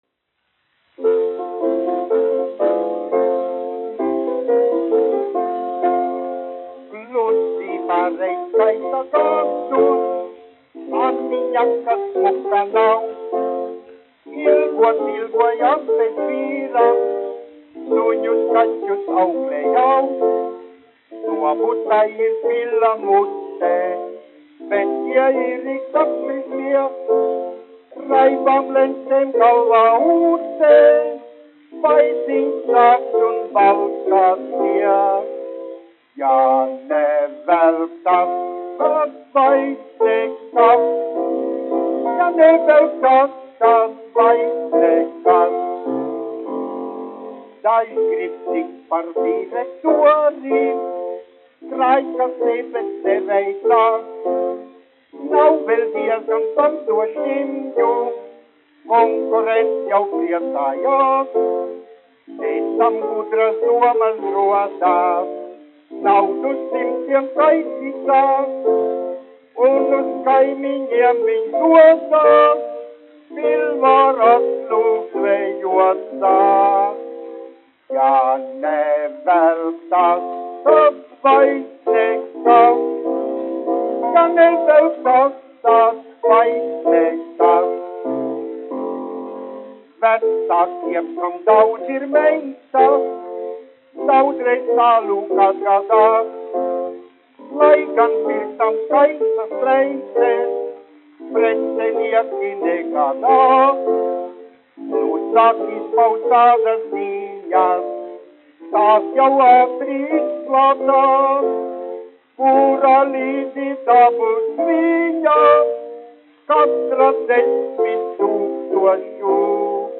1 skpl. : analogs, 78 apgr/min, mono ; 25 cm
Populārā mūzika
Humoristiskās dziesmas
Skaņuplate